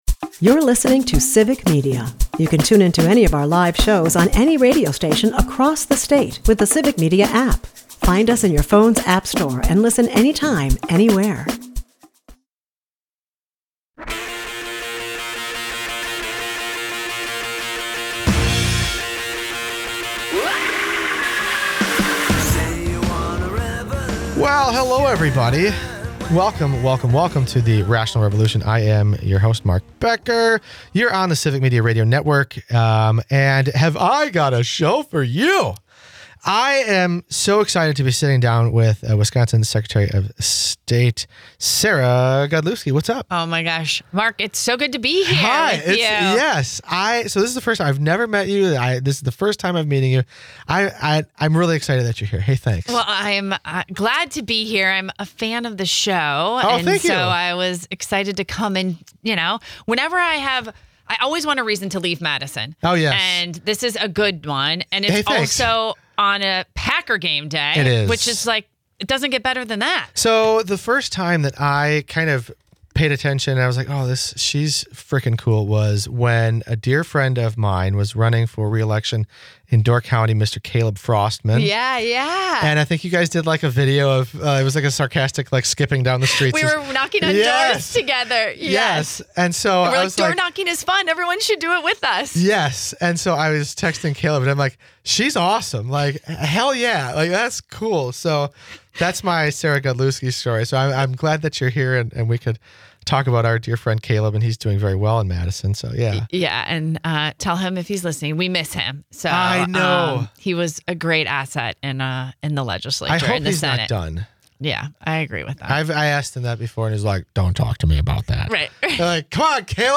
Guests: Secretary of State, Sarah Godlewski